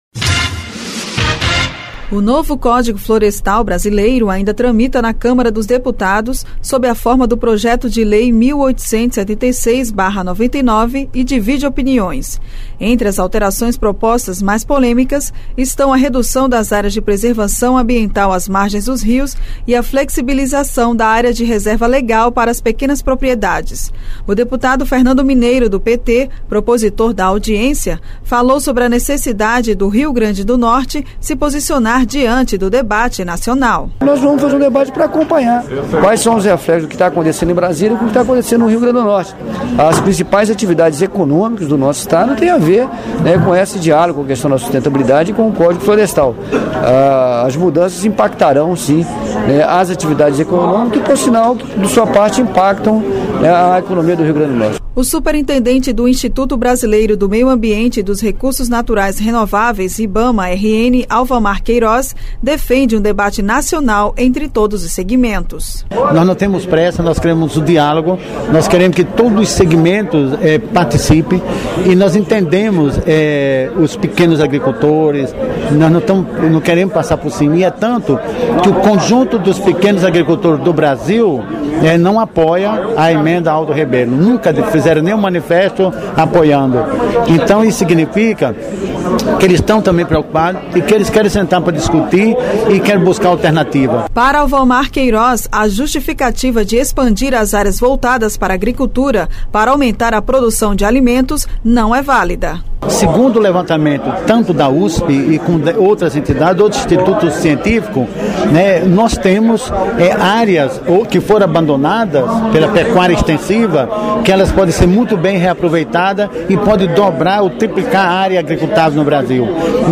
Programa diário com reportagens, entrevistas e prestação de serviços